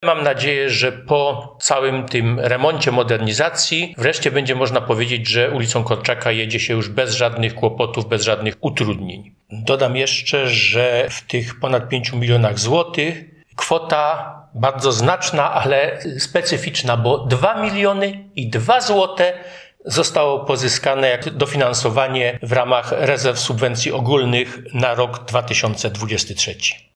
– Mam nadzieję, że po tej modernizacji ulicą Korczaka będzie się jeździło bez żadnych kłopotów – mówi starosta cieszyński Mieczysław Szczurek.